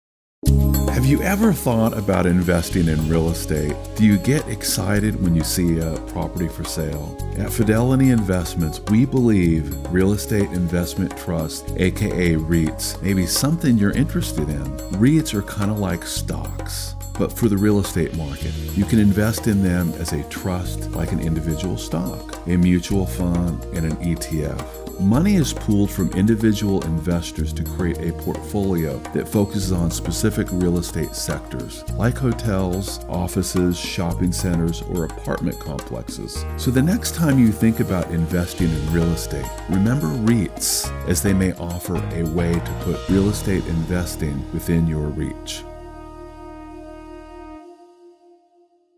talented male voiceover artist known for his warm, rich tone that captivates listeners